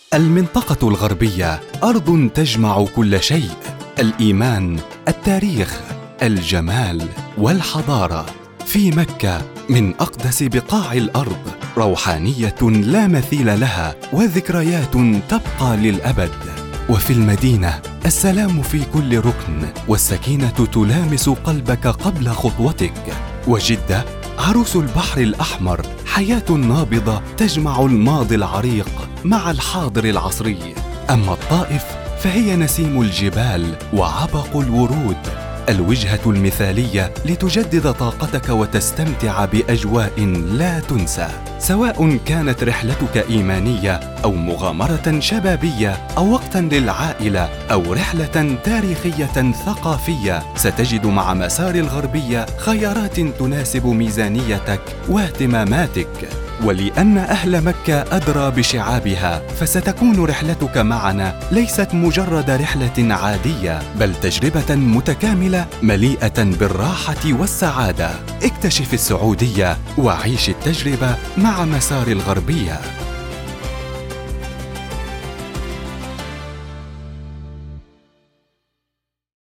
تعليق صوتي فصحي حماسي اعلان سياحي Standard Arabic
عينة صوتية حماسية بأسلوب تحفيزي عربي فصيح، مستوحاة من طابع الإعلانات السينمائية مثل إعلان مسار الغربية أداء قوي ومفعم بالطاقة، مناسب للبروموهات، الحملات الإعلانية، الإعلانات الملهمة، والمشاريع الترويجية التي تتطلب نبرة واثقة ومؤثرة Promotional Standard Arabic Voice Over with a cinematic motivational tone, ideal for high-energy commercials, brand promos, and inspiring advertising campaigns